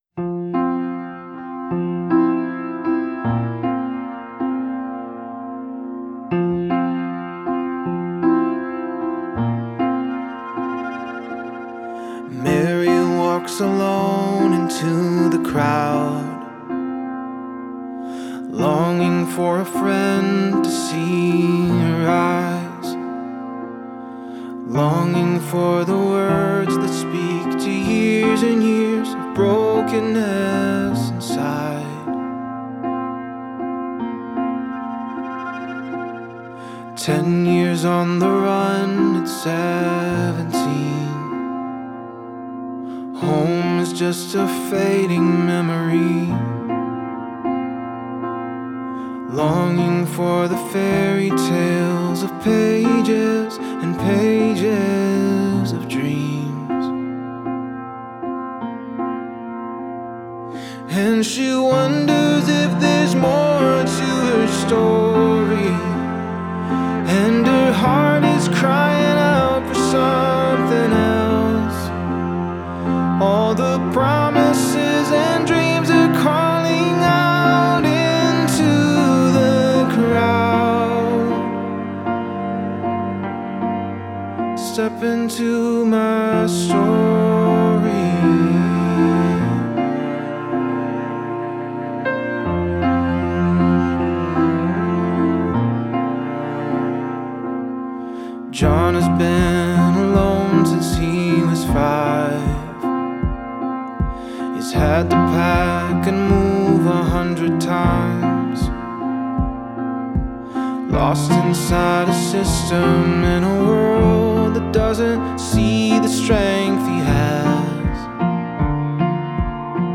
Independent singer-songwriter and pianist